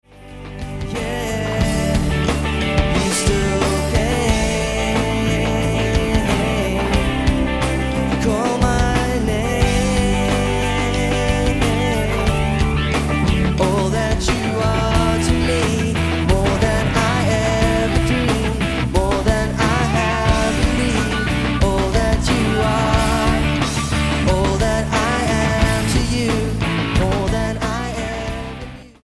STYLE: Pop
recorded live